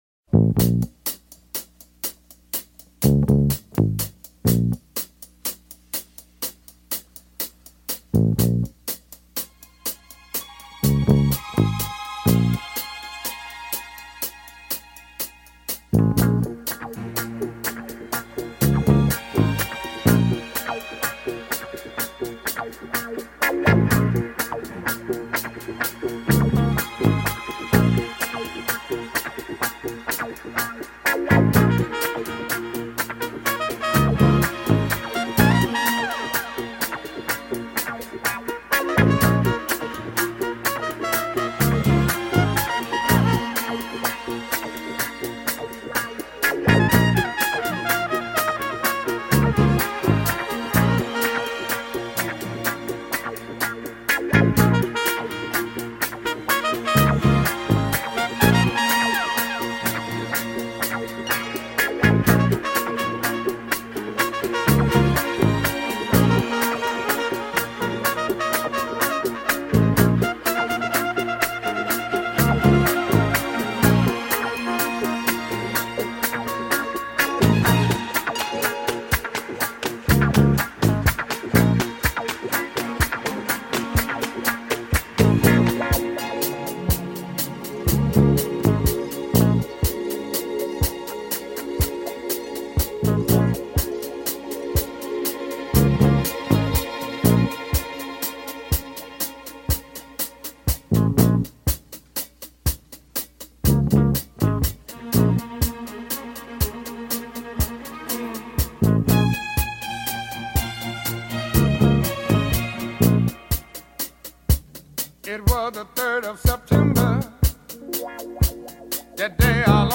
Funky